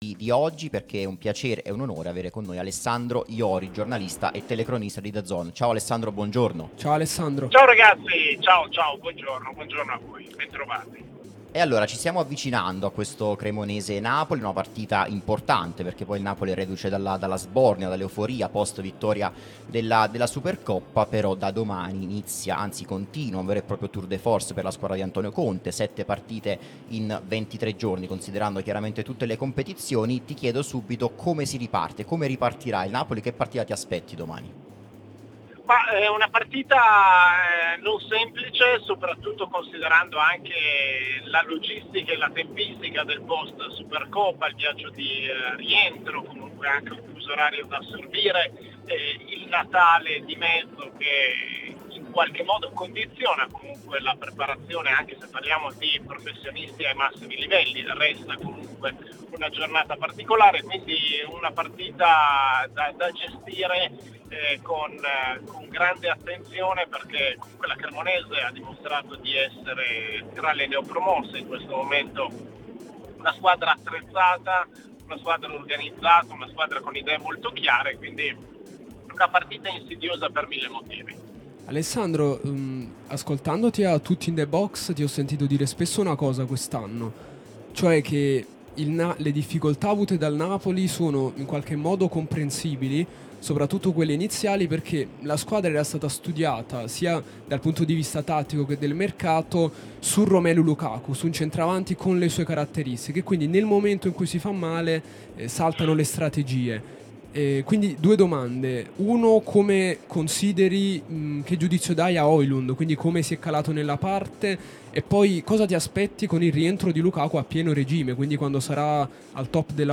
sulla nostra Radio Tutto Napoli, prima radio tematica sul Napoli